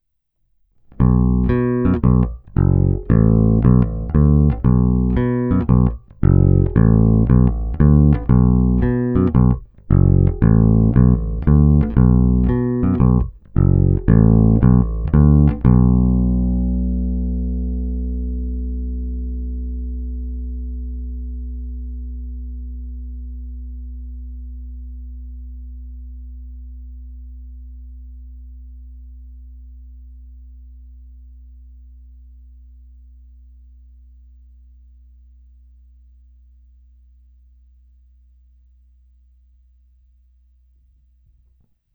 Zvuk je opravdu šedesátkový, středobasový, ne tak ostrý jako padesátkové nebo sedmdesátkové kousky, ale není ani zahuhlaný.
Není-li uvedeno jinak, následující nahrávky jsou provedeny rovnou do zvukové karty, jen normalizovány, jinak ponechány bez úprav.
Hráno vždy s plně otevřenou tónovou clonou.
Hra u kobylky